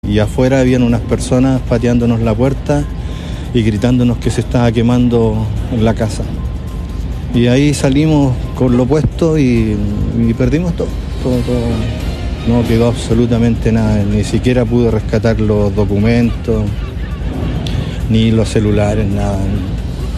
En este sentido, uno de los damnificados contó a Radio Bío Bío el momento en que se enteraron del incendio y los minutos posteriores.